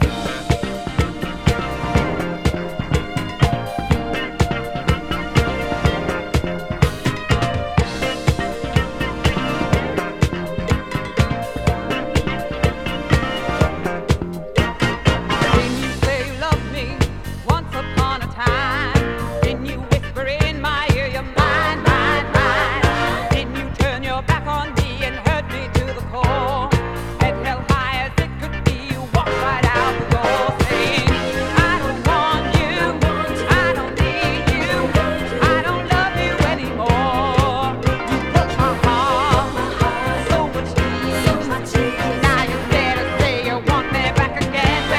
Disco, Funk, Soul　USA　12inchレコード　33rpm　Stereo